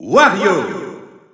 Announcer pronouncing Wario's name in French.
Wario_French_Announcer_SSBU.wav